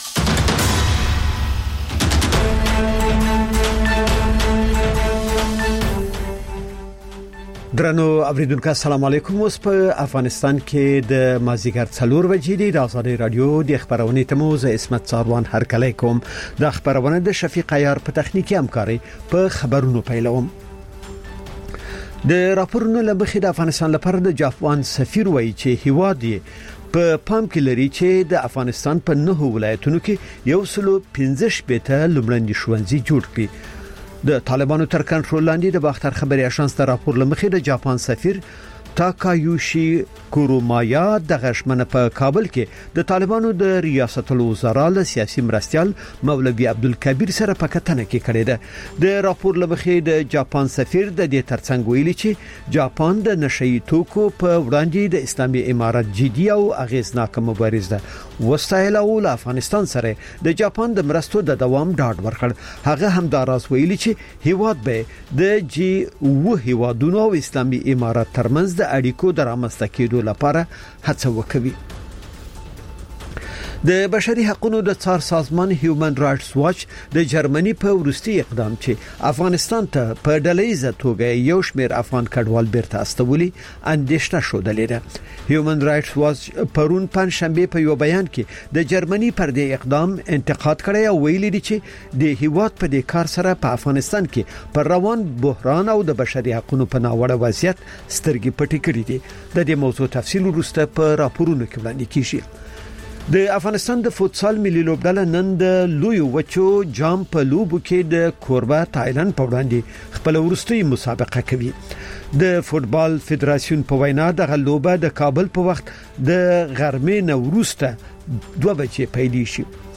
مازیګرنی خبري ساعت - P1 سټوډیو